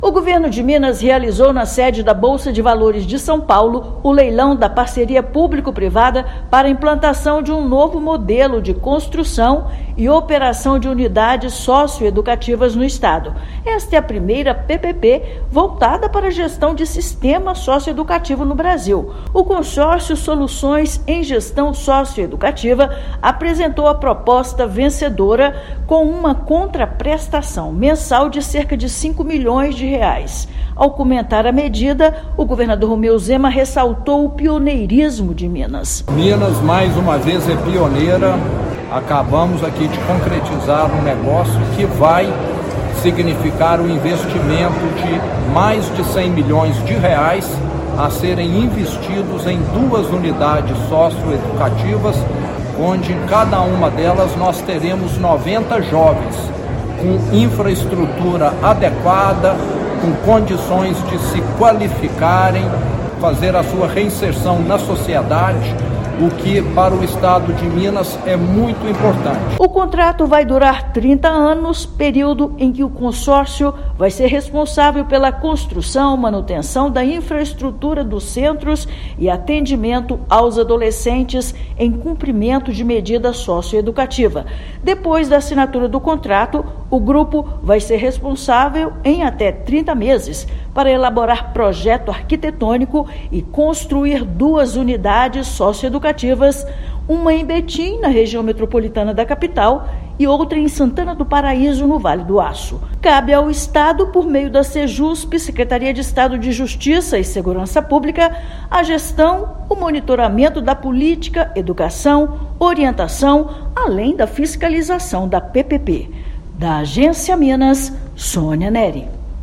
Consórcio vencedor será responsável pela construção de duas novas unidades, além da oferta de serviços voltados ao atendimento de adolescentes. Ouça matéria de rádio.